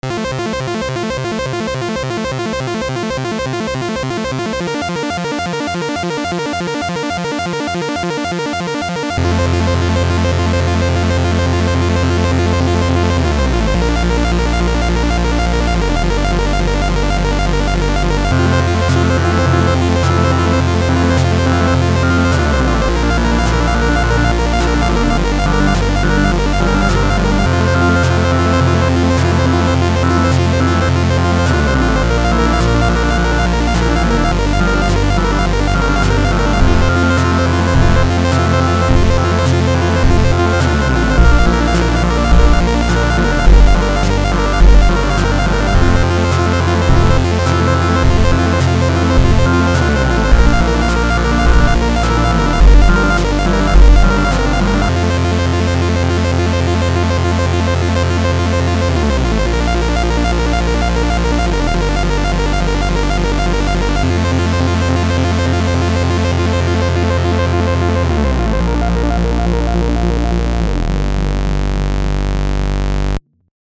8-bit Song
Made in FL Studio using Kepler Exo.
solo instrument